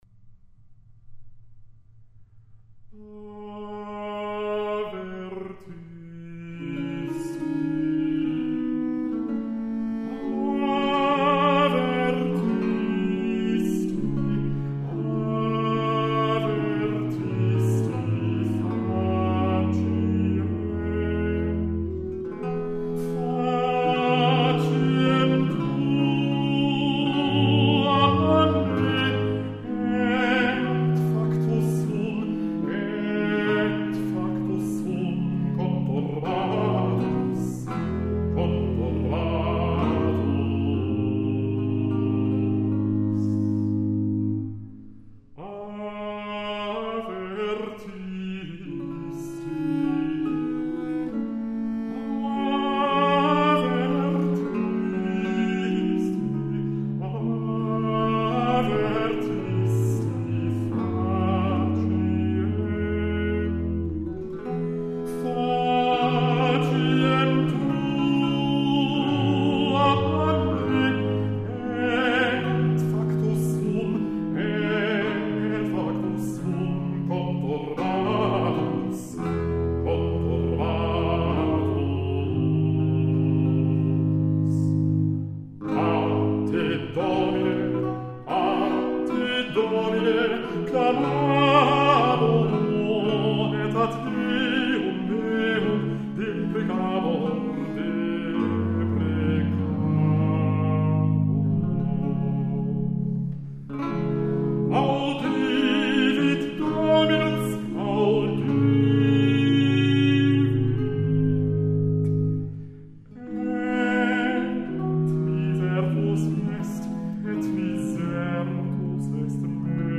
theorbe
orgel.